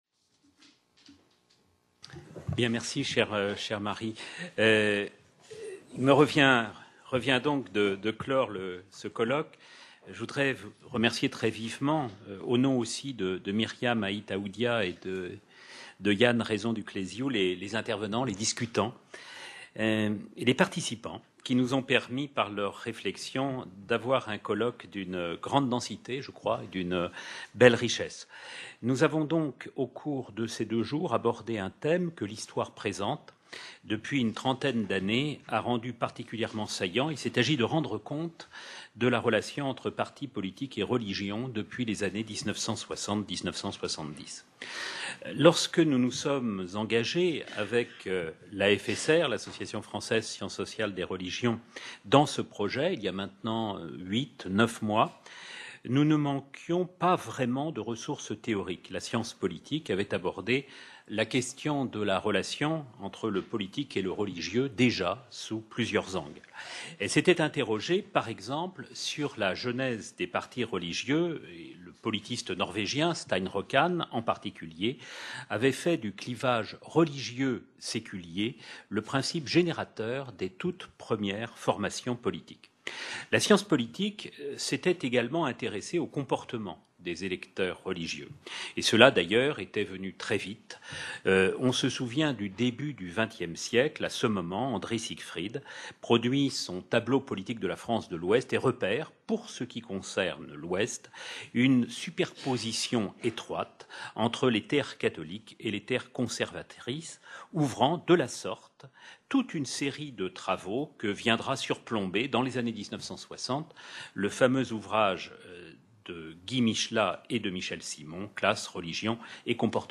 Conclusion à un colloque comparant des religions | Canal U